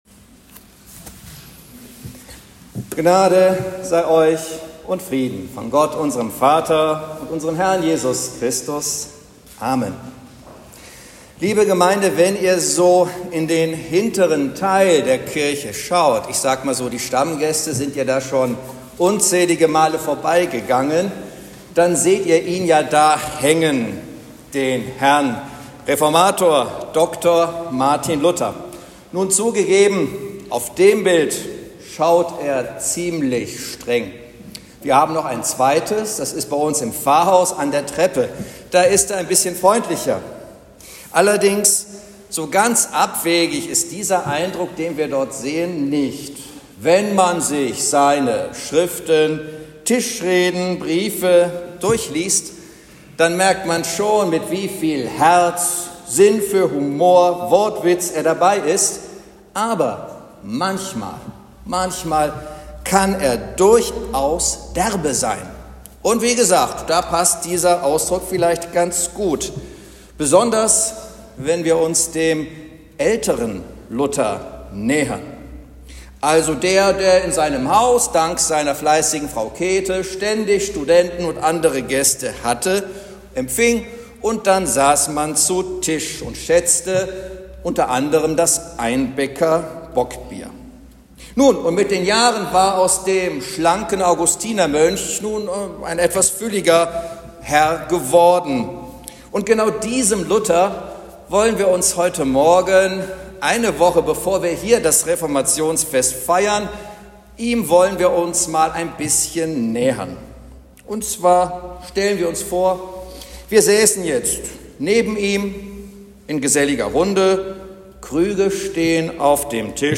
Predigt zum 18. Sonntag nach Trinitatis - vom 19.10.2025